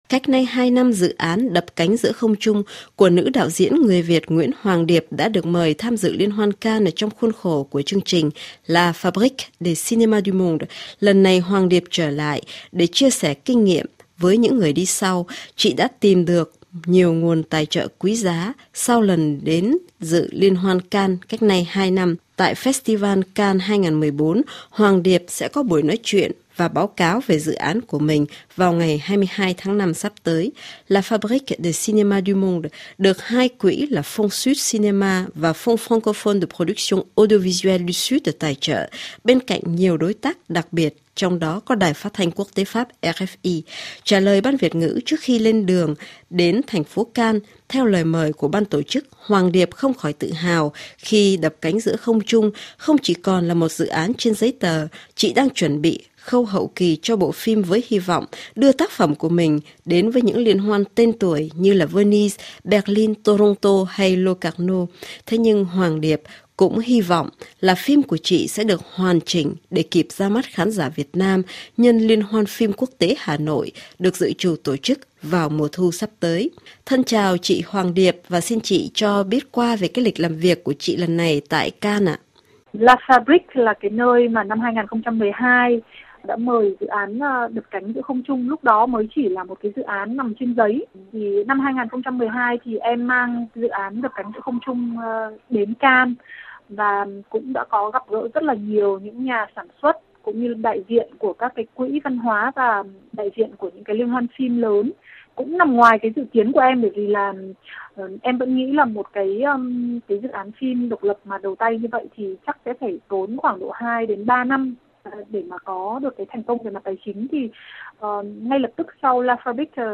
RFI phỏng vấn đạo diễn